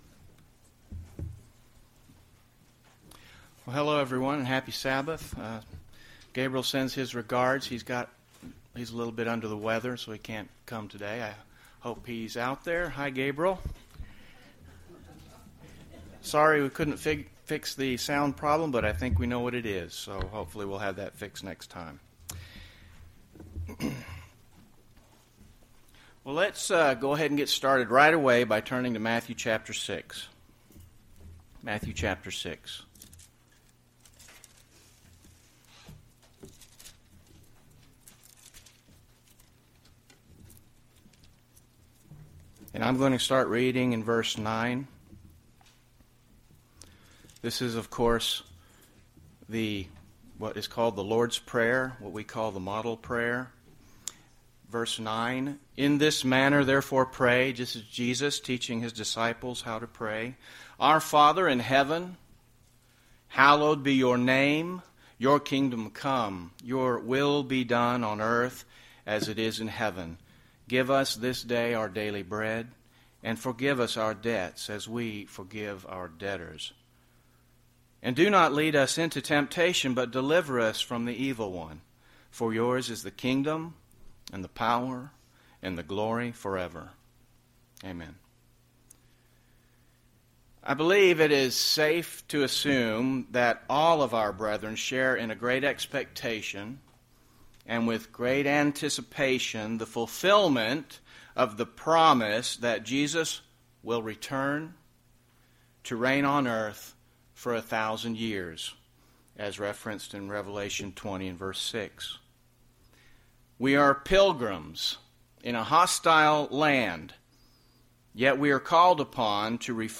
UCG Sermon Thy Kingdom Come Kingdom of God Kingdom of Heaven Notes PRESENTER'S NOTES Let’s get started by turning right away to Matthew 6…